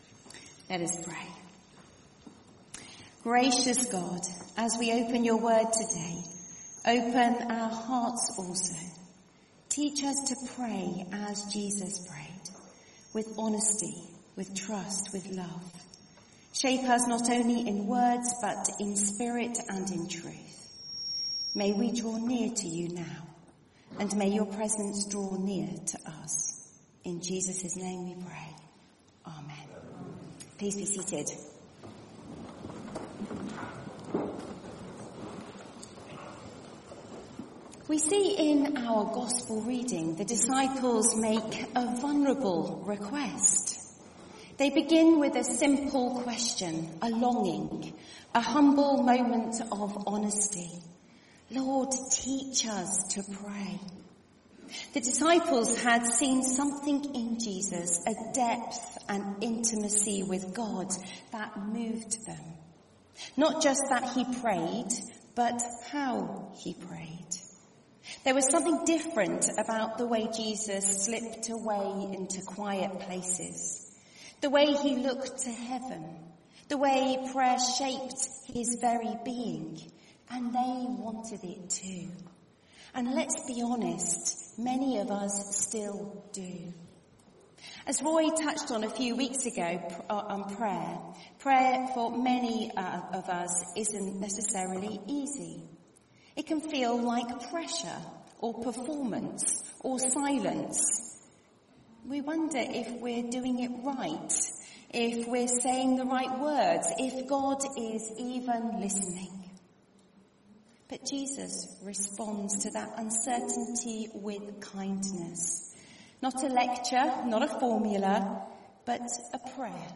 This is the Gospel of the Lord All Praise to you, O Christ Series: Ordinary Time , Sunday Morning